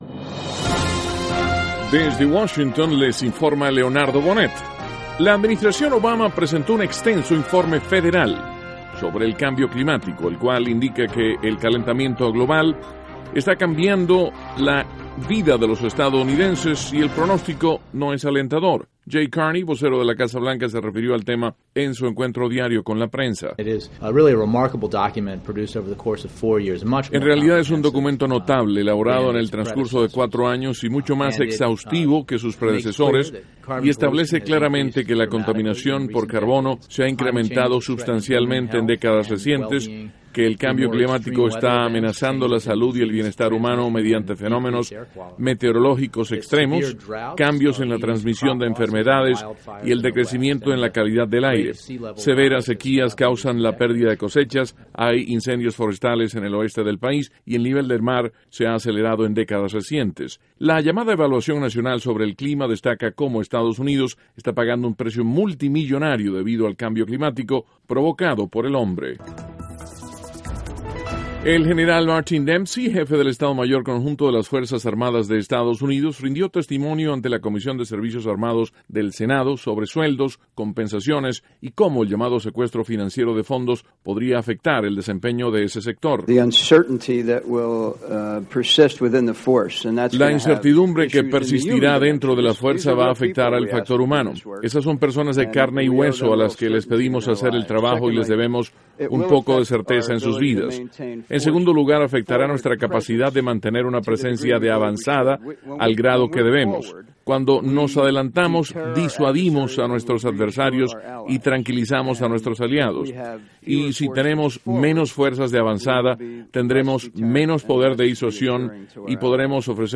NOTICIAS - MARTES, 6 DE MAYO, 2014